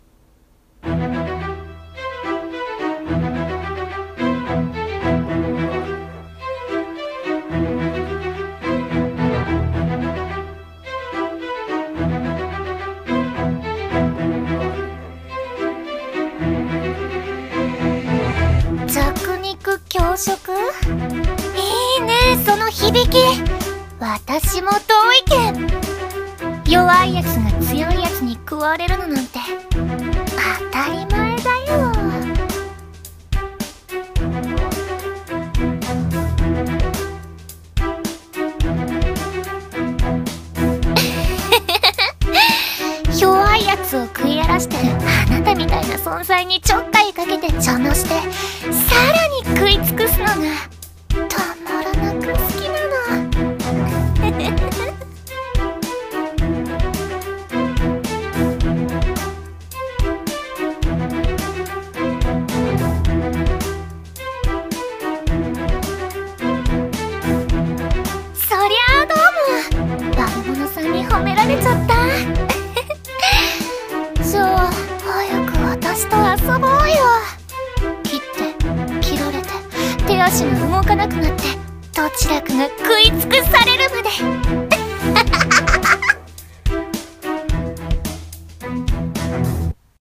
声劇】この世は弱肉強食【掛け合い